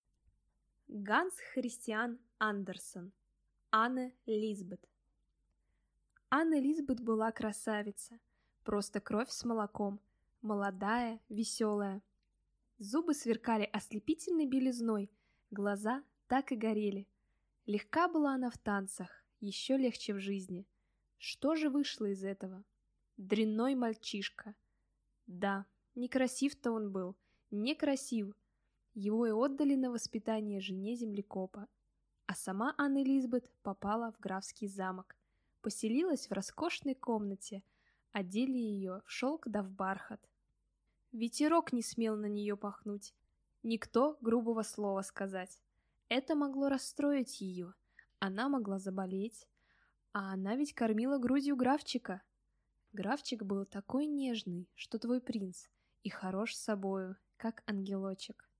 Аудиокнига Анне Лисбет | Библиотека аудиокниг